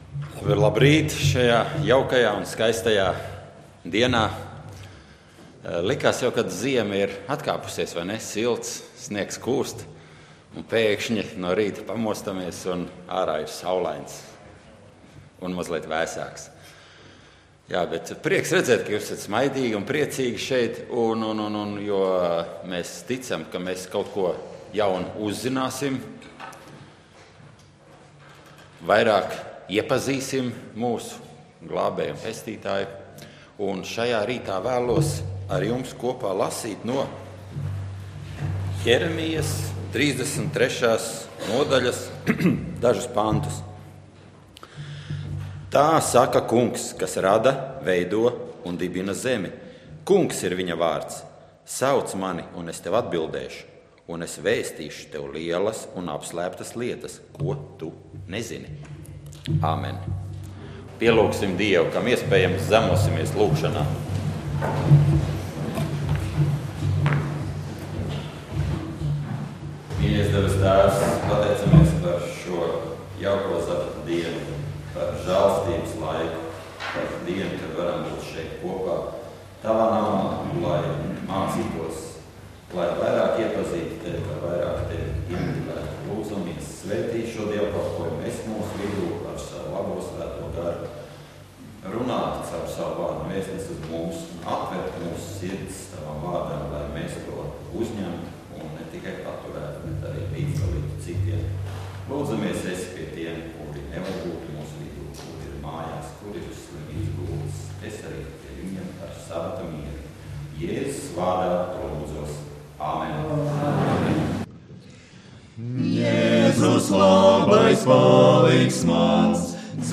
Dievkalpojums 23.03.2013: Klausīties
Svētrunas